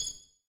menuclick.ogg